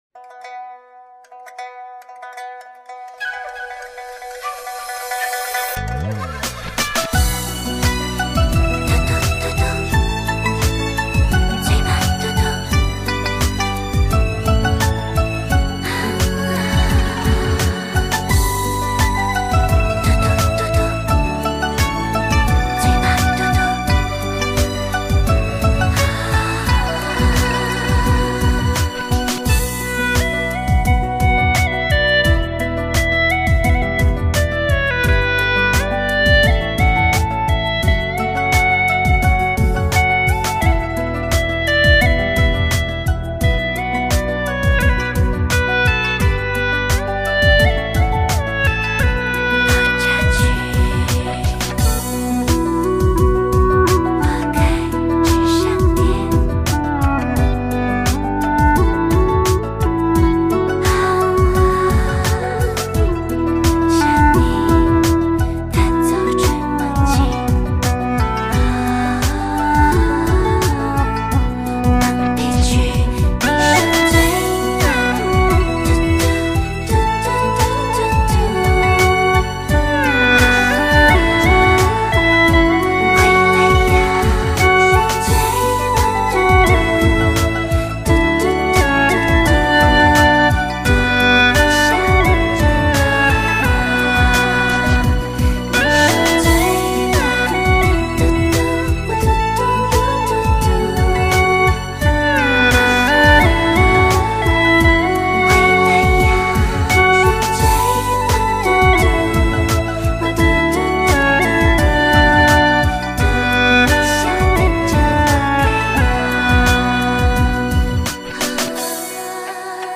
调式 : D
【大小D调】 我要评论